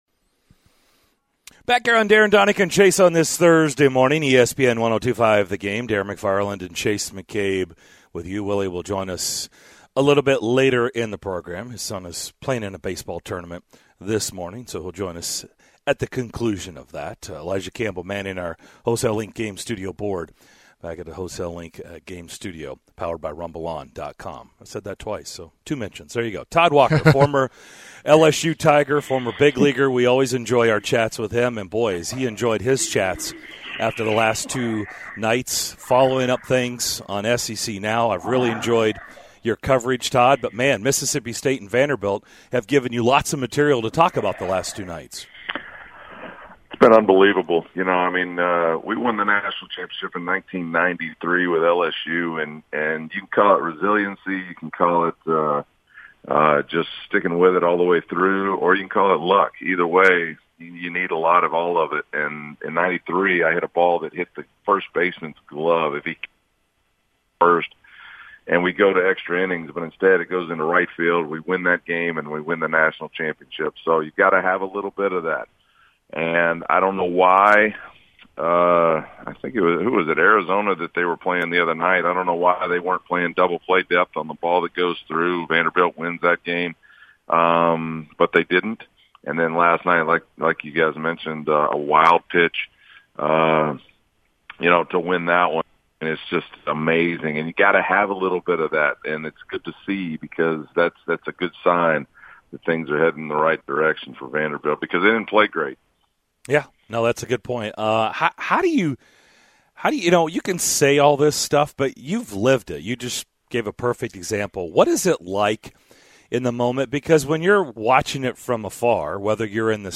SEC Network baseball analyst Todd Walker joined the DDC to breakdown Vanderbilt's thrilling College World Series win over Stanford, the coaching hunt for LSU baseball and more!